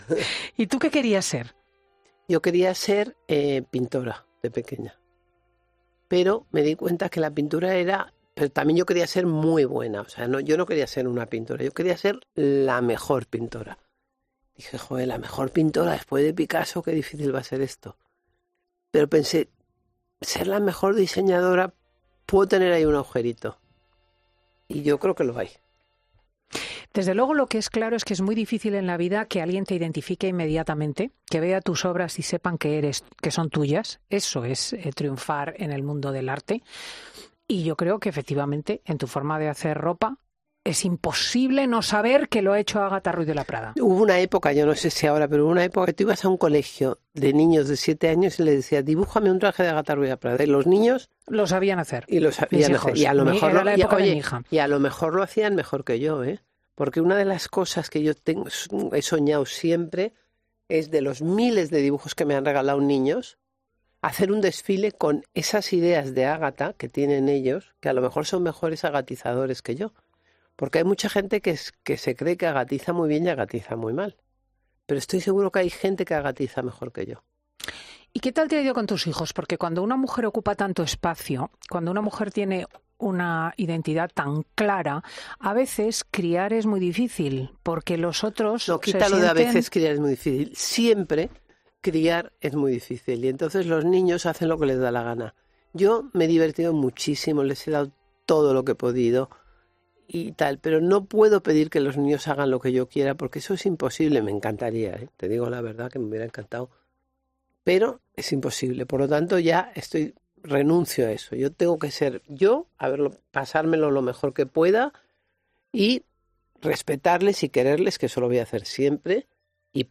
Además, lo ha hecho en absoluta confianza, porque siente un cariño muy especial por Cristina López Schlichting, como ha dicho nada más comenzar la entrevista.